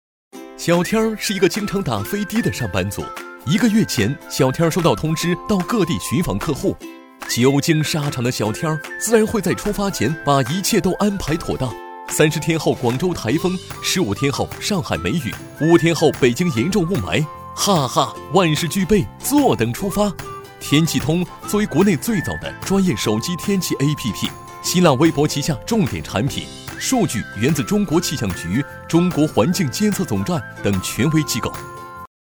病毒配音